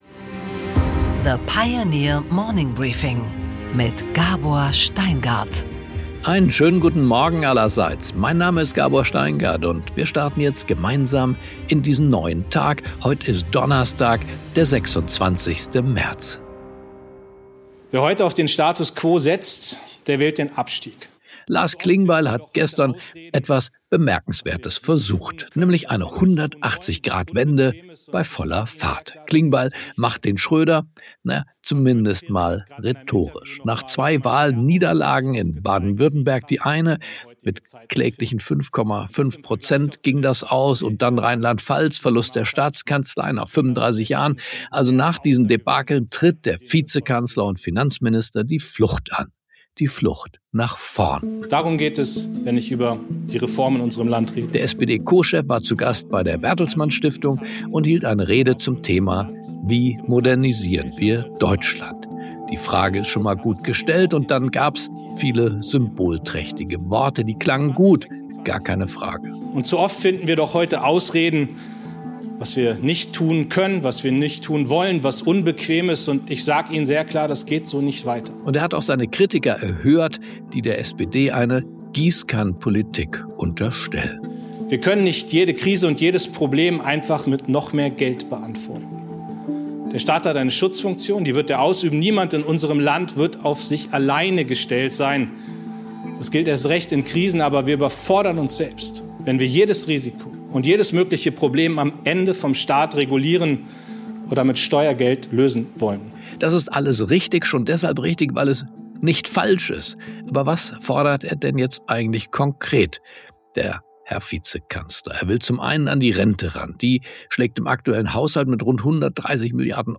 Gabor Steingart präsentiert das Morning Briefing.
Interview